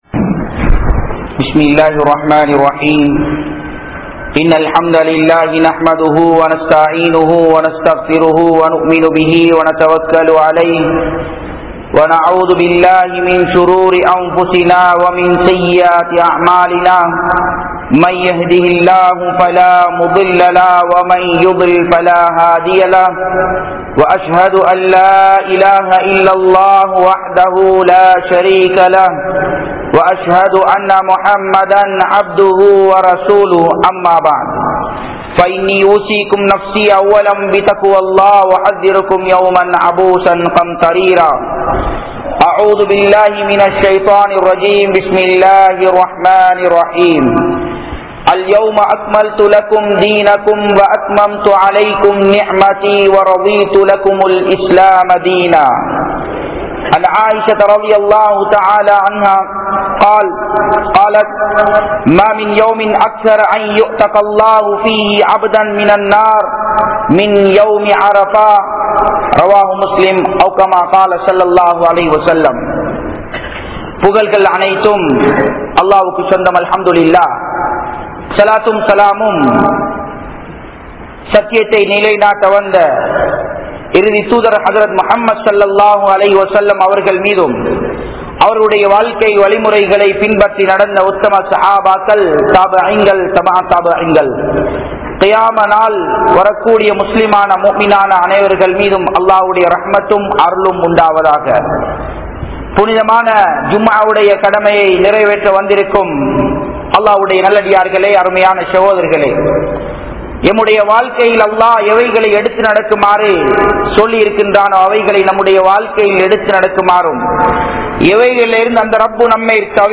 Arafa Thinaththin Sirappuhal (அரபா தினத்தின் சிறப்புகள்) | Audio Bayans | All Ceylon Muslim Youth Community | Addalaichenai